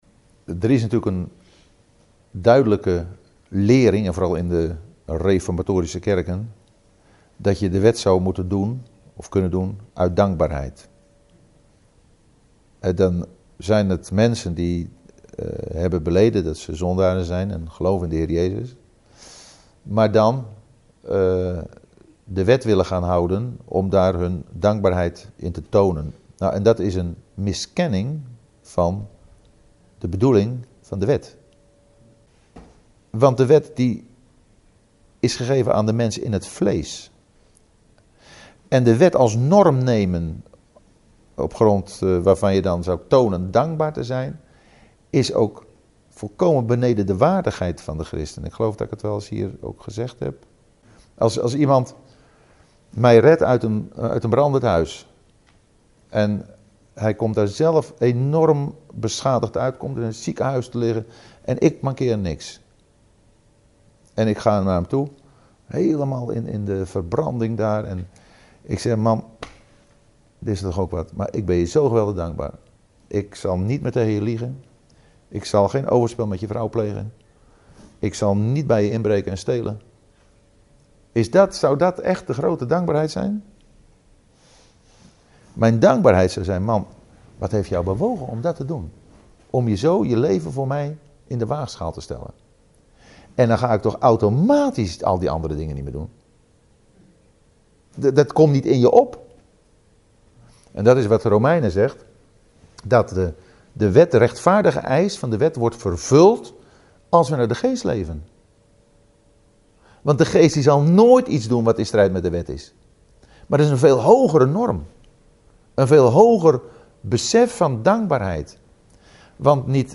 Deze preek is onderdeel van de serie:
Na een bijbelstudie over 2 Timotheus 2 en 3 vroeg een van de aanwezigen: "Moeten wij, christenen, de wet uit dankbaarheid vervullen?".